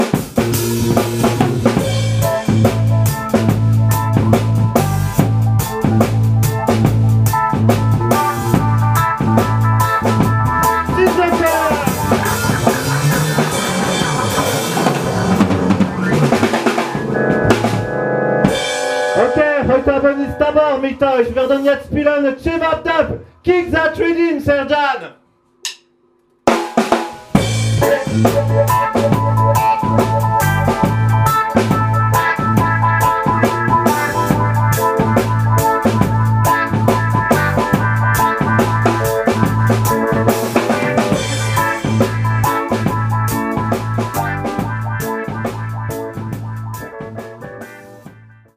Live Dub
Bass Guitar, Voice